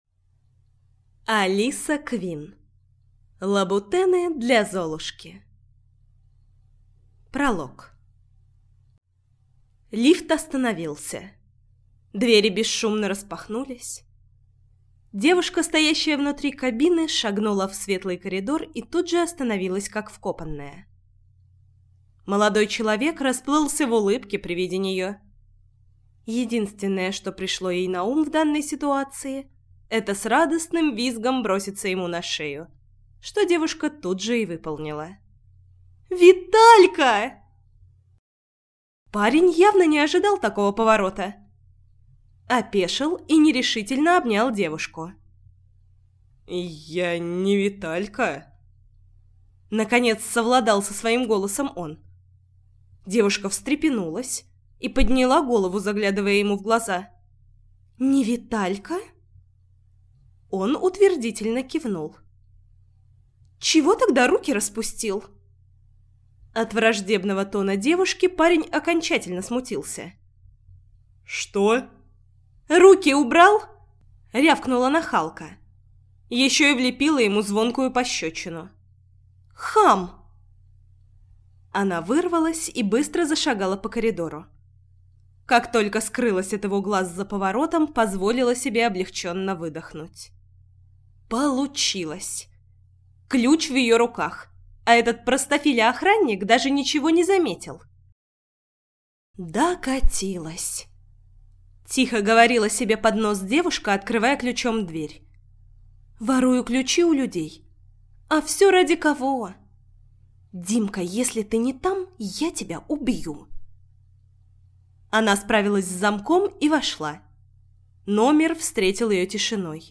Aудиокнига Лабутены для Золушки